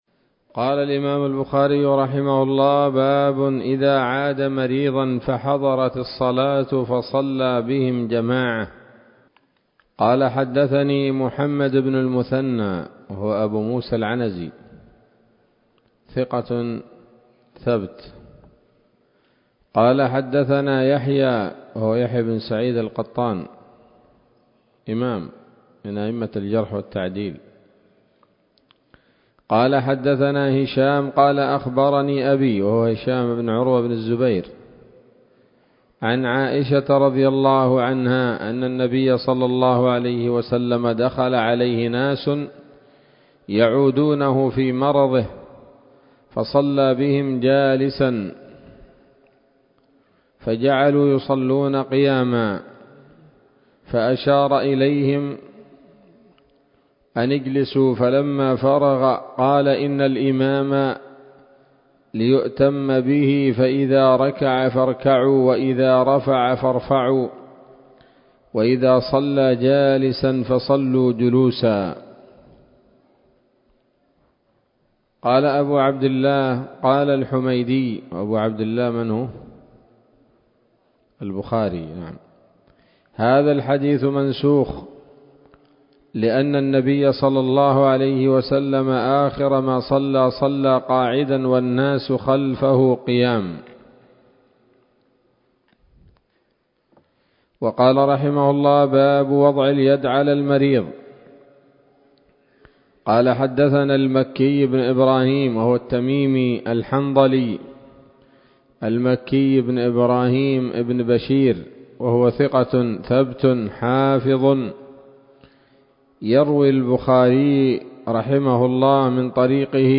الدرس العاشر من كتاب المرضى من صحيح الإمام البخاري